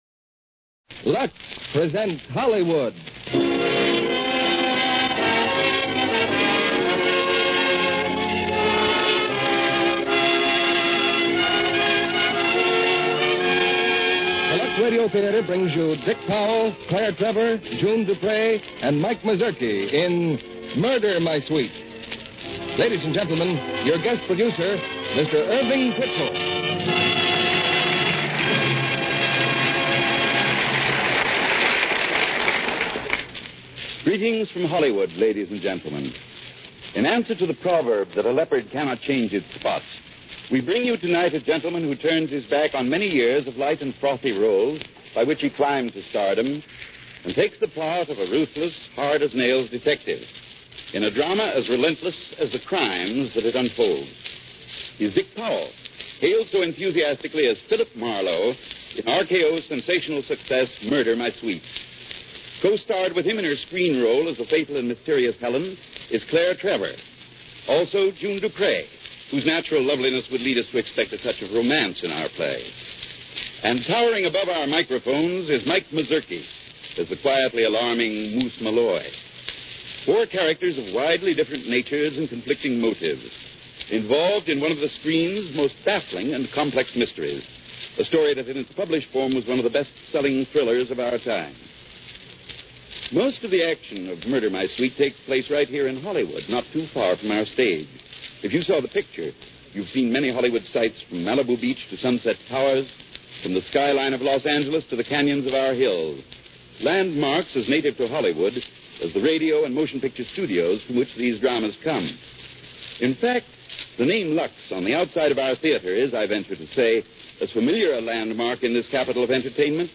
Lux Radio Theater Radio Show